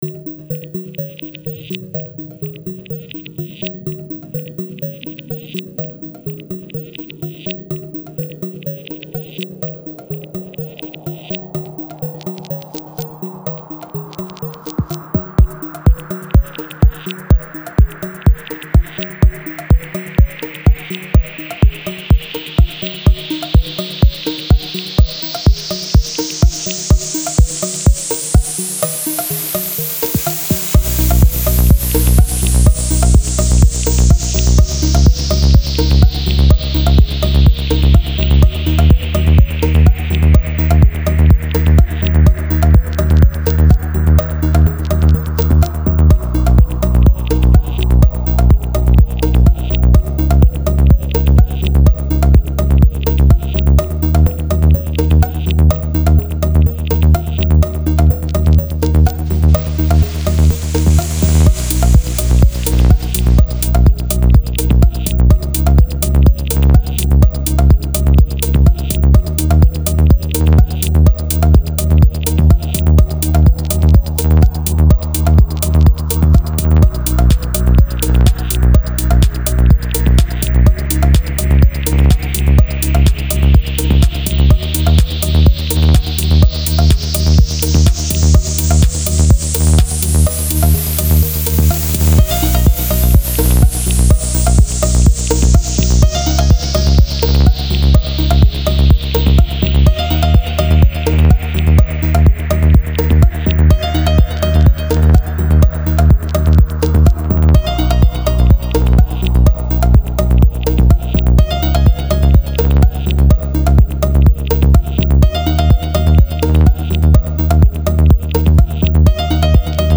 Genre: Neo Trance